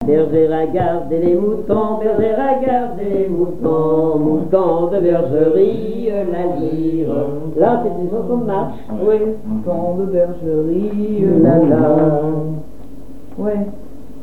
Genre laisse
Conversation autour des chansons et interprétation
Pièce musicale inédite